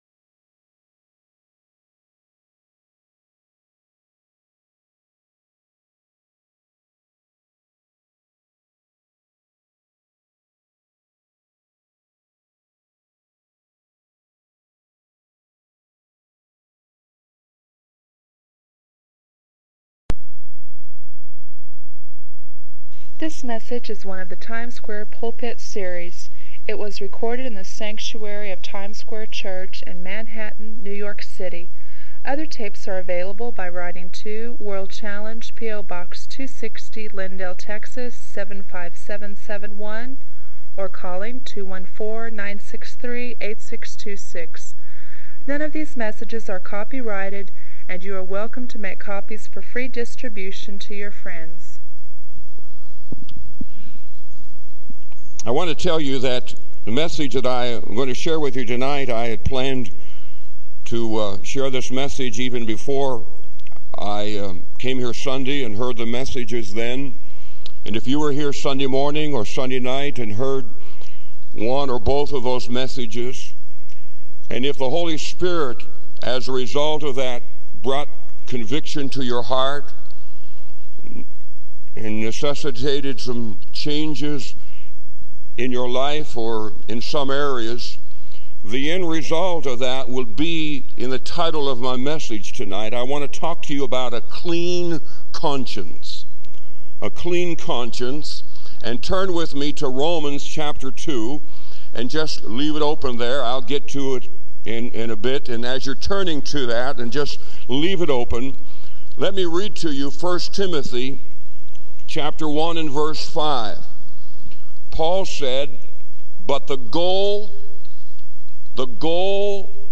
This sermon is part of the Times Square Pulpit series and aims to disciple the entire congregation toward spiritual maturity.